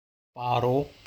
Paro (PA-ro)